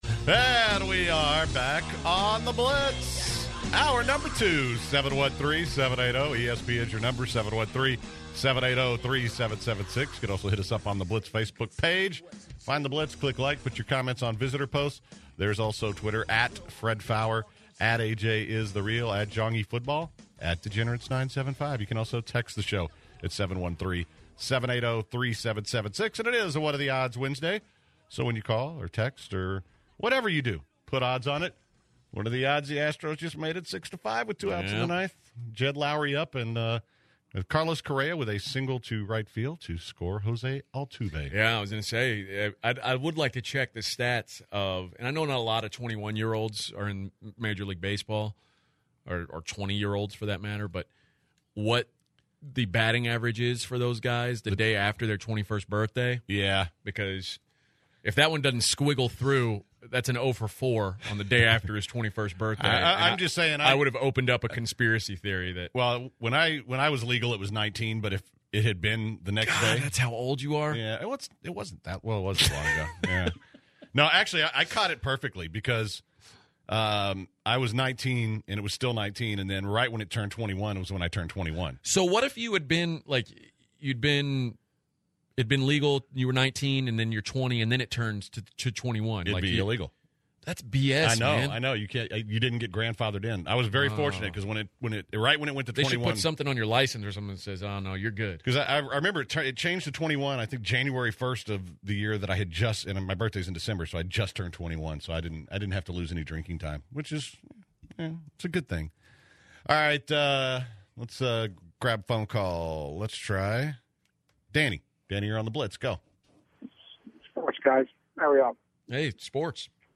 What Are The Odds Wednesday continues as the guys take calls, talk betting, college football, poop, and the Gem of the Day.